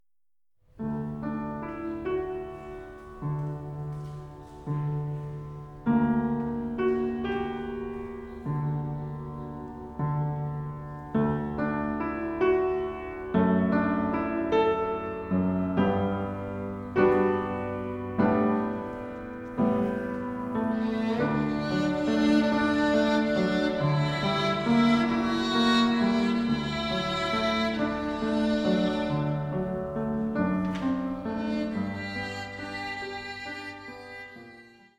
Streichensemble, Klavier
• kurzweilige Zusammenstellung verschiedener Live-Aufnahmen